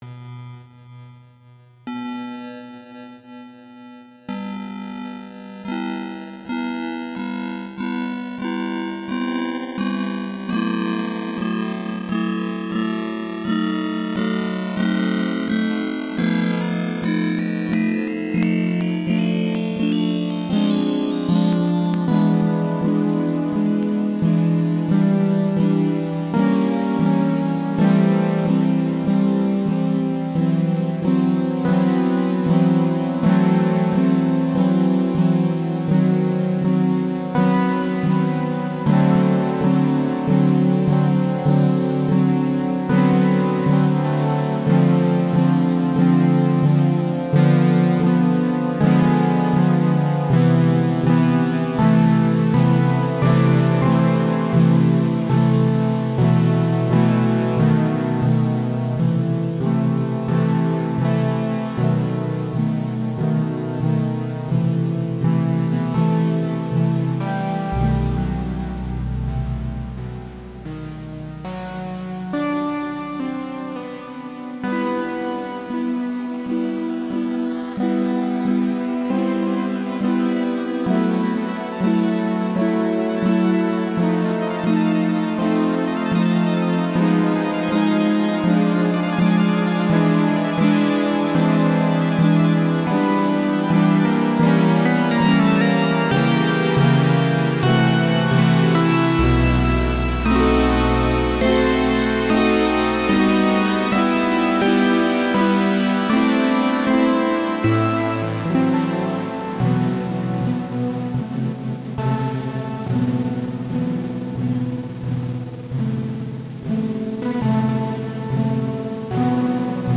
Ambient electronic sound